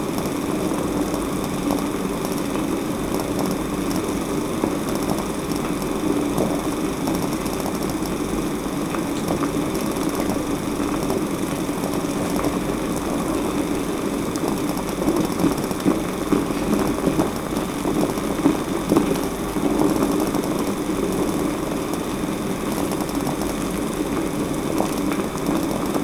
Cocer agua
Sonidos: Agua
Sonidos: Hogar